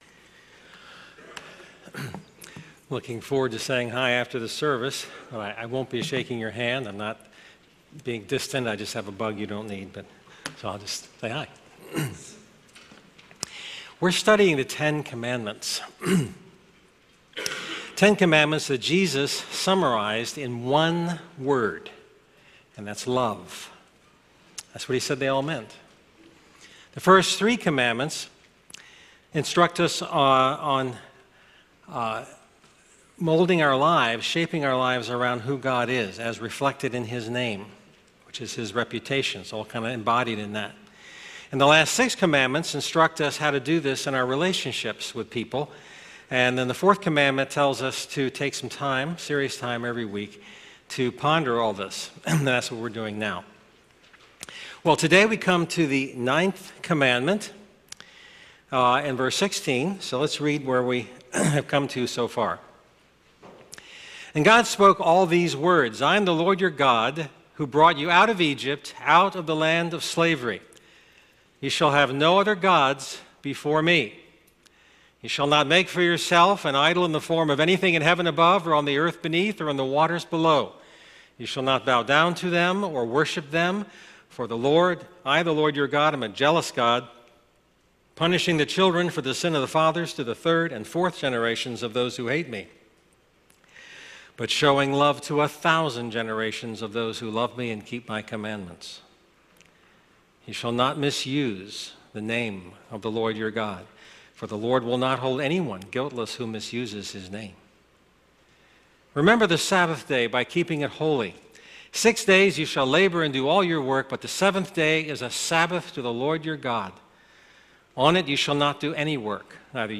Sermons – GrowthGround